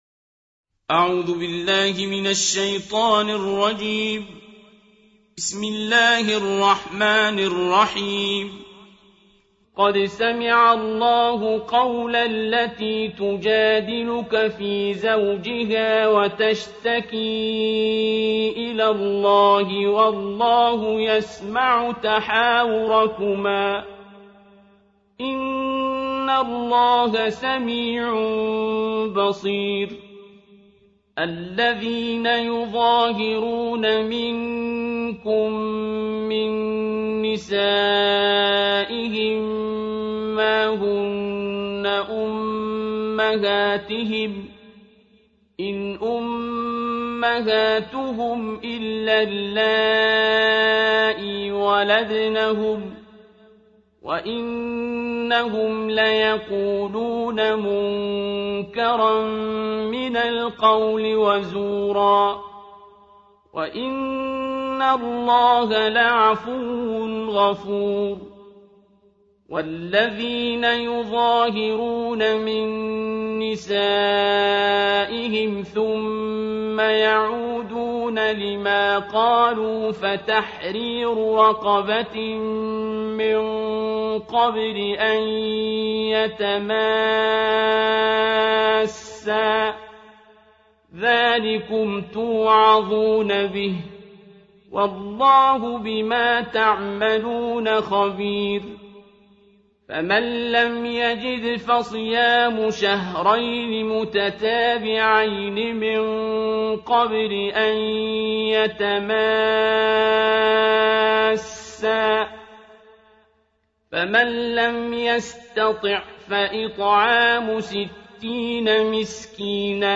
دانلود جزخوانی قرآن با صدای استاد عبدالباسط
جز بیست و هشتم قرآن کریم را با صدای استاد عبدالباسط را در این خبر بشنوید و دانلود کنید.